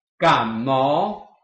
臺灣客語拼音學習網-客語聽讀拼-饒平腔-單韻母
拼音查詢：【饒平腔】mo ~請點選不同聲調拼音聽聽看!(例字漢字部分屬參考性質)